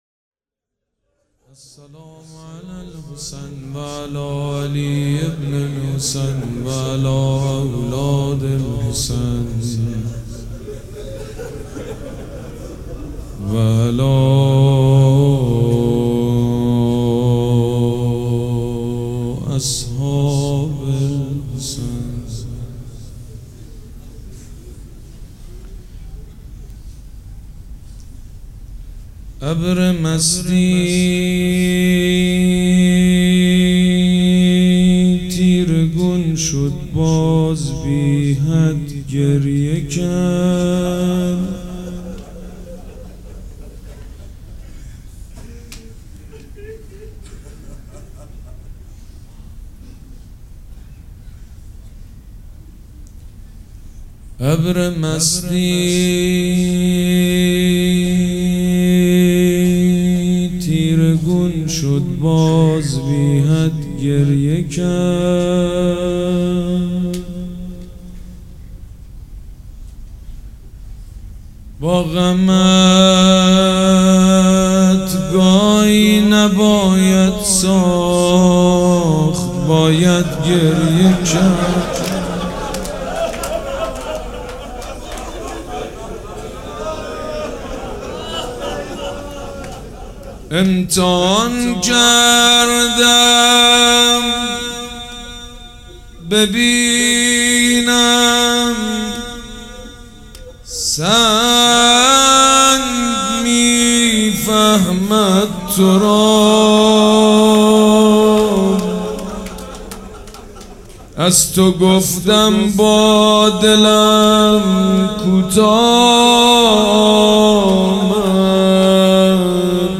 روضه
مراسم عزاداری شب سوم